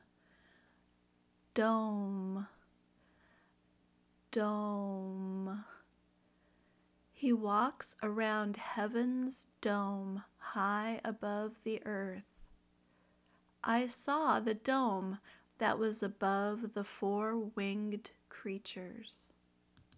/ʊm/ (noun)